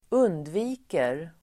Uttal: [²'un:dvi:ker]